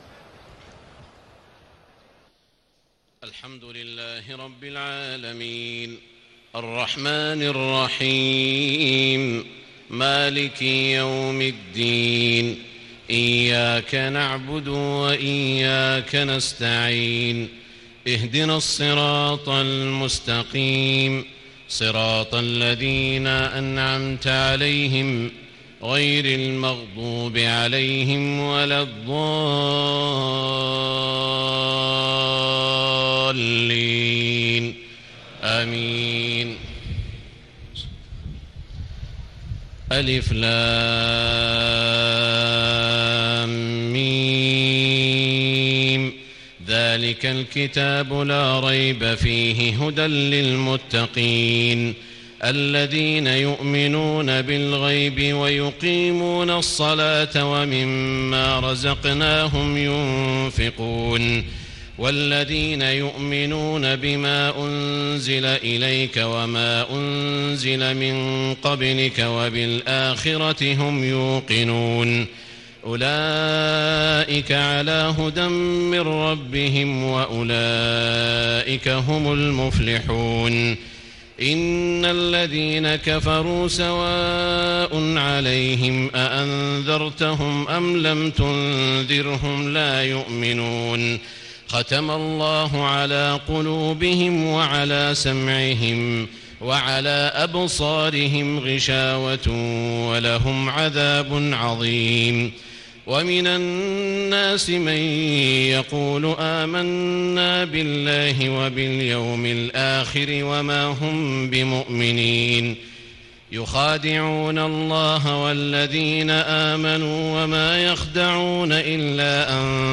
تهجد ليلة 21 رمضان 1436هـ من سورة البقرة (1-91) Tahajjud 21 st night Ramadan 1436H from Surah Al-Baqara > تراويح الحرم المكي عام 1436 🕋 > التراويح - تلاوات الحرمين